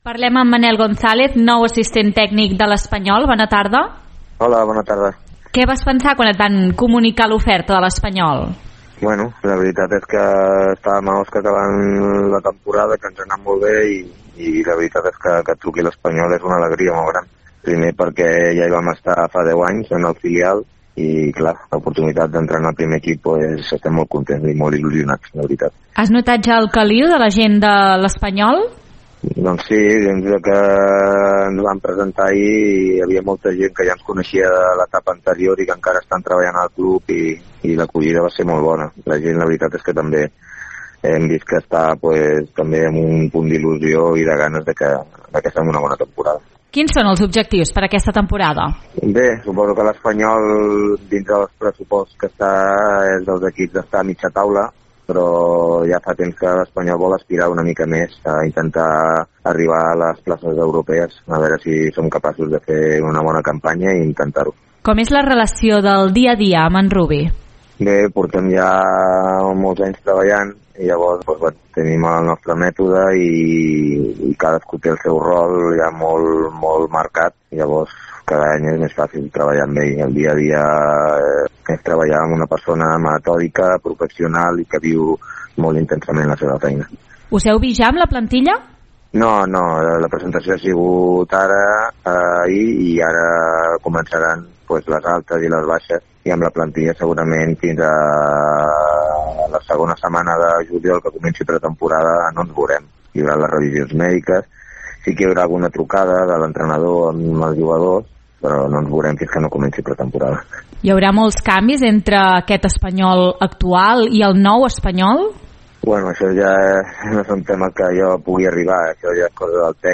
En una entrevista a l’Info Vespre